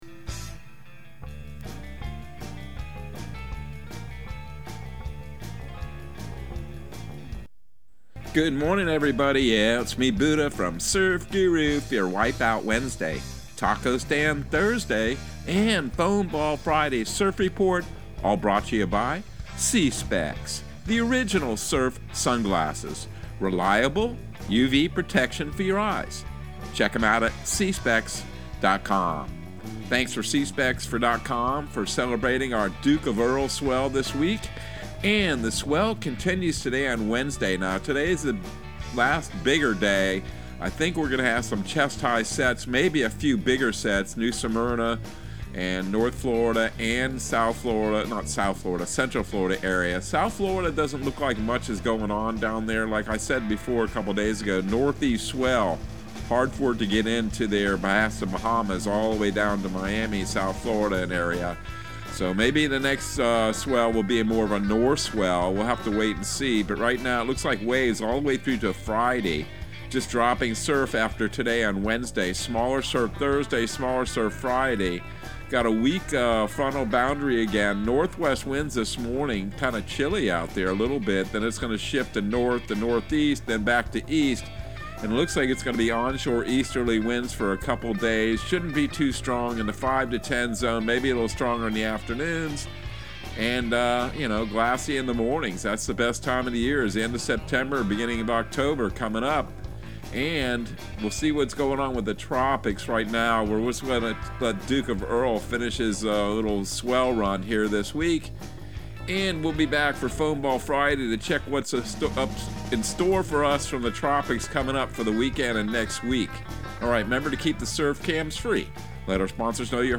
Surf Guru Surf Report and Forecast 09/14/2022 Audio surf report and surf forecast on September 14 for Central Florida and the Southeast.